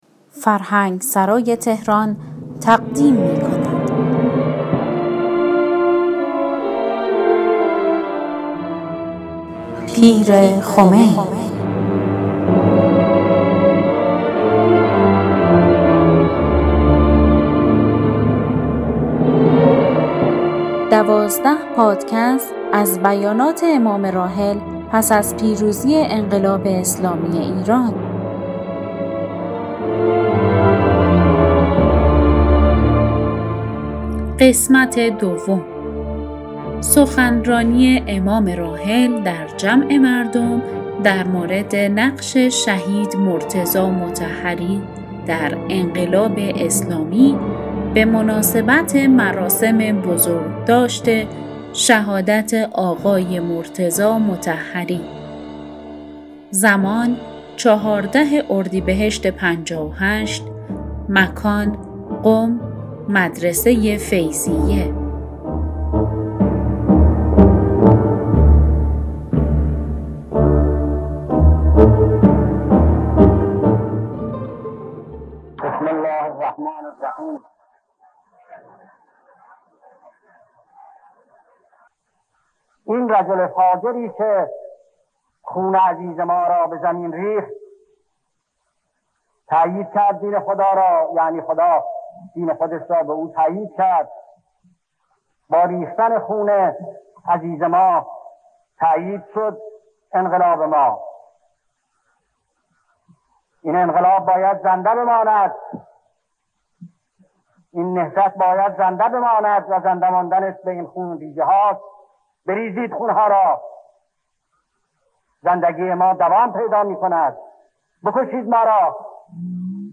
در ادامه سخنان امام (ره) را درباره نقش شهید مرتضی مطهری در انقلاب اسلامی که به مناسبت سالروز شهادت این شهید بزرگوار در اردیبهشت ماه سال 1358 در مدرسه فیضیه قم ایراد کردند، می‌شنویم.